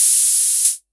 Vermona Open Hat 05.wav